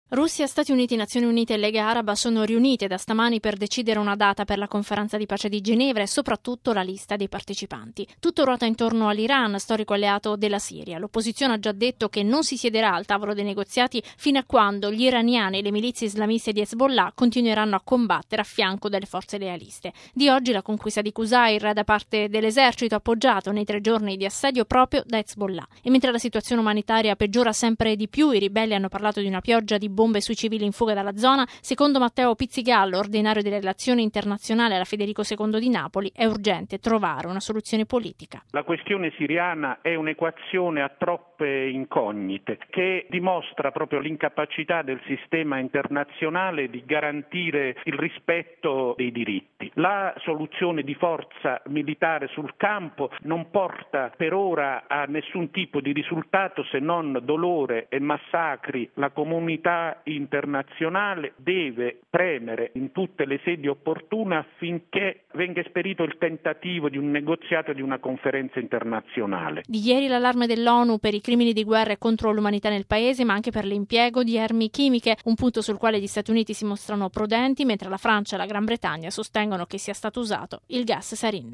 Intanto, a Ginevra si è aperta la riunione preparatoria in vista della Conferenza di pace sulla Siria. Il servizio